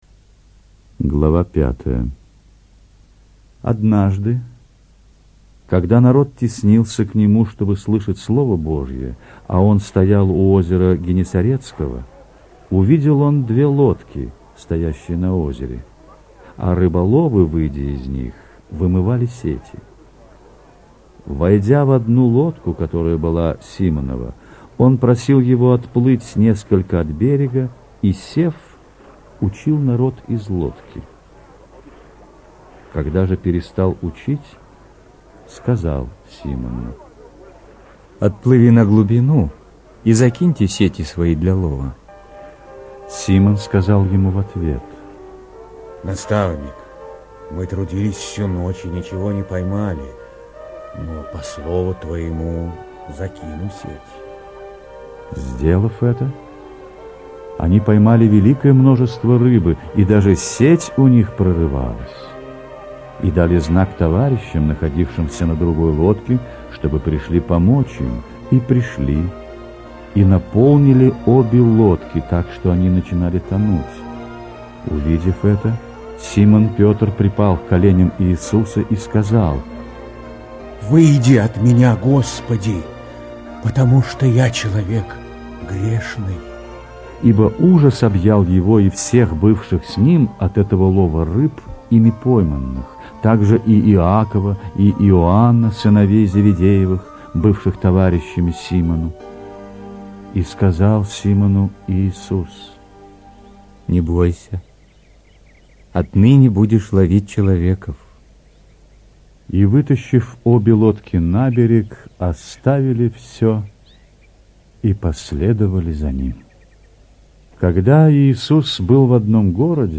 инсценированная аудиозапись    Подробнее...